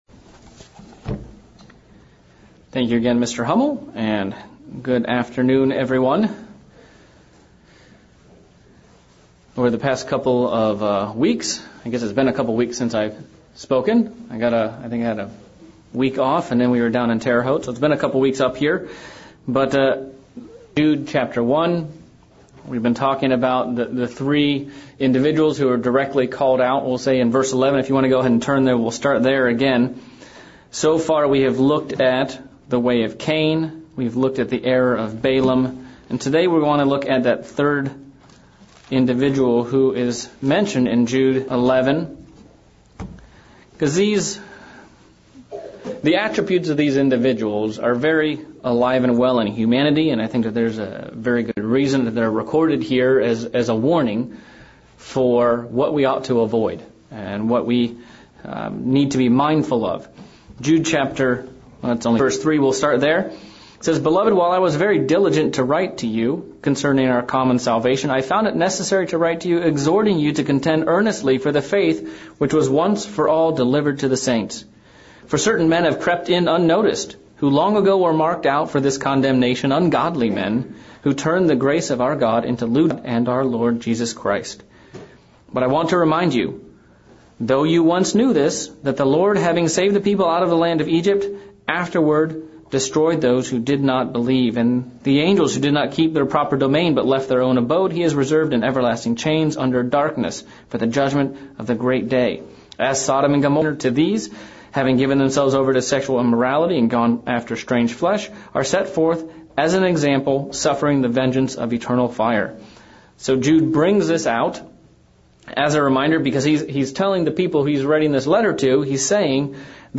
Part 3 of the sermon series on Jude :11 - What was the rebellion of Korah? Sermon looking at how we can avoid this and what we can do when we have disagreements with God's chosen leaders.